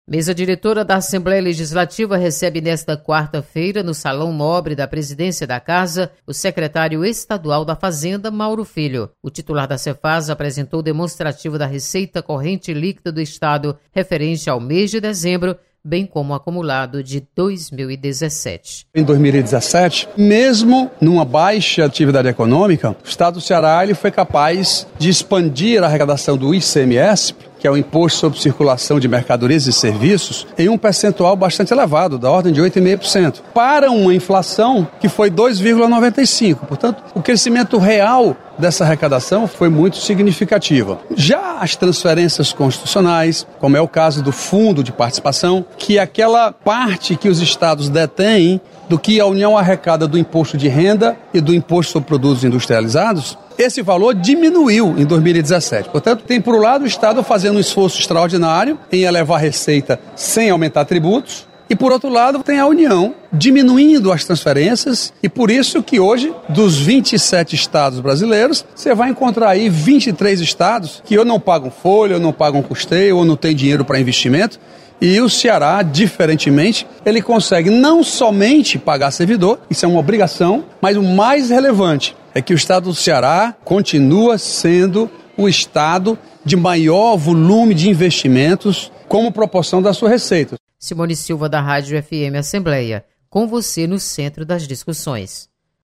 Secretário da fazenda apresenta relatório da receita corrente líquida do Estado. Repórter